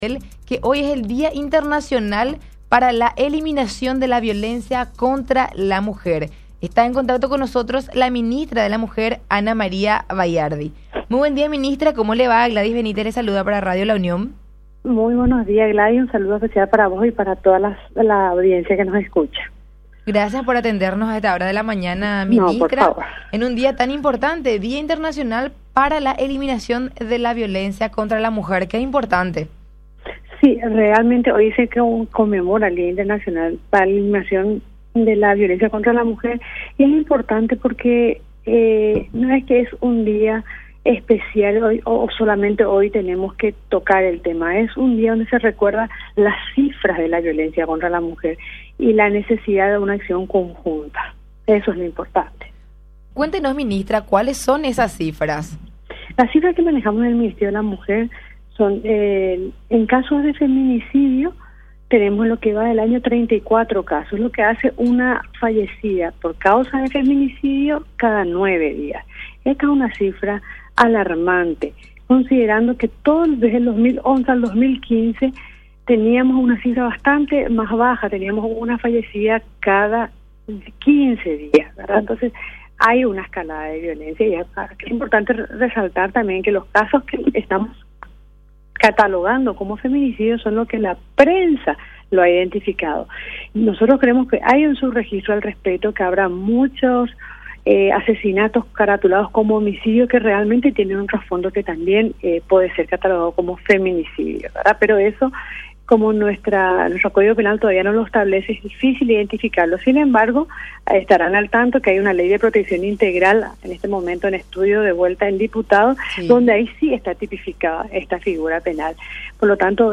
La Ministra Ana María Baiardi del Ministerio de la Mujer en contacto con La Unión AM dialogó sobre el Día Internacional de la Eliminación de la violencia contra la mujer. Manifestó que en Paraguay hay cifras alarmantes en cuanto a casos de feminicidio.